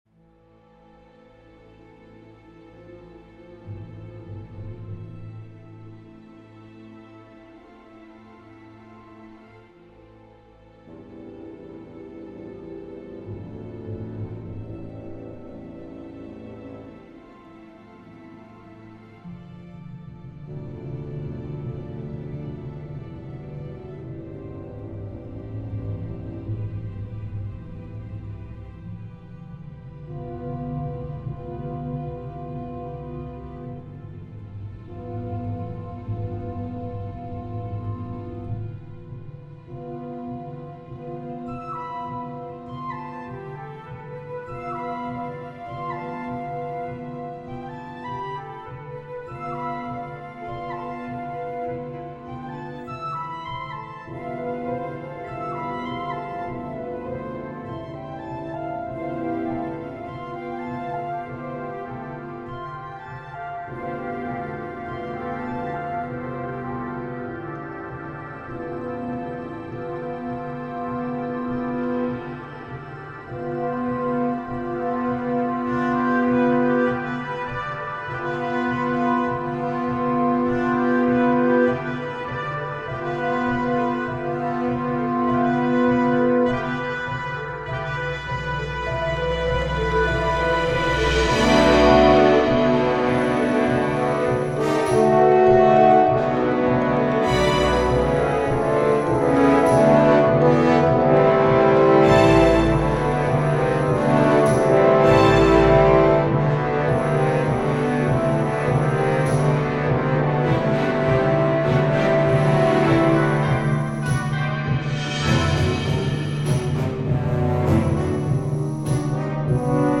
Whale and the Whaler - Orchestral and Large Ensemble - Young Composers Music Forum